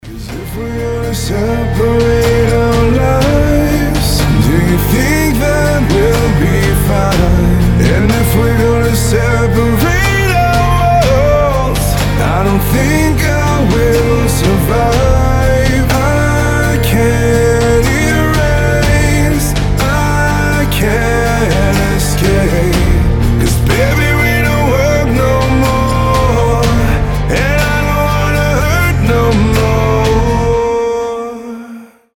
• Качество: 320, Stereo
грустные
мелодичные
Alternative Rock
красивый мужской вокал
лирические
баллада